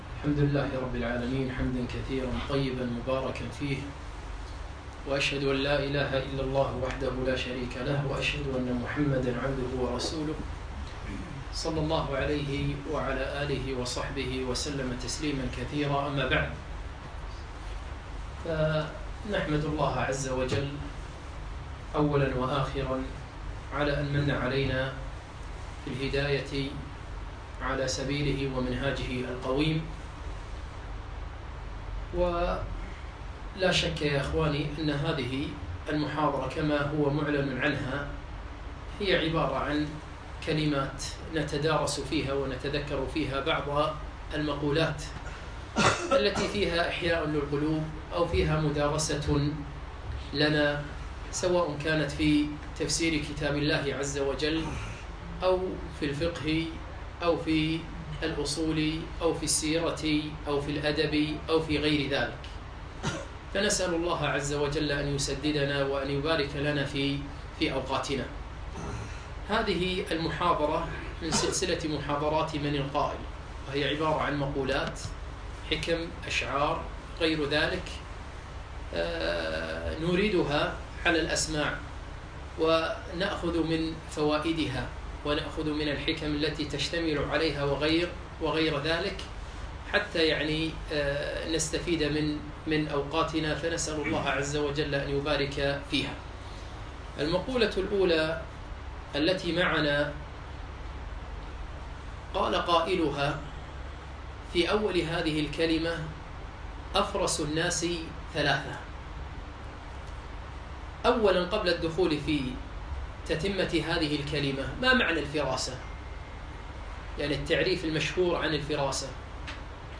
6- من القائل ؟ - المحاضرة السادسة